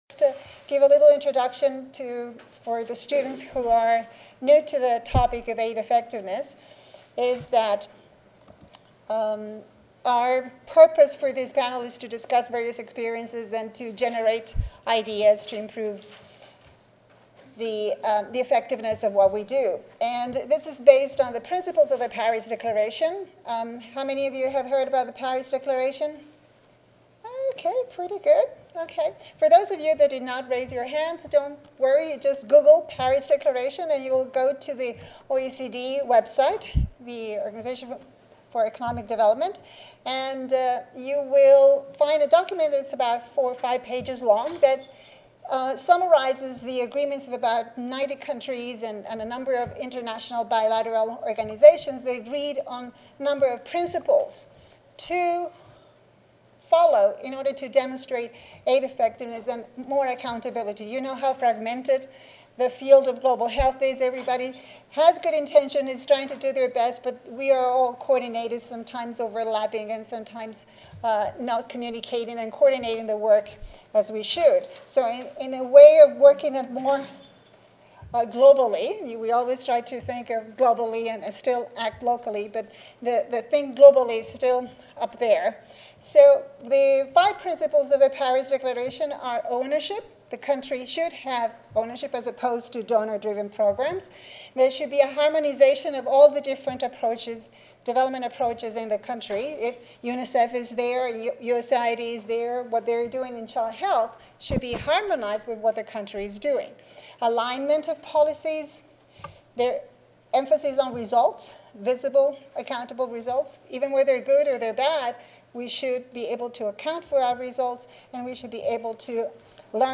This presentation will be part of an invited panel presentation on a simple model to decide what and how to monitor accountability and aid effectiveness.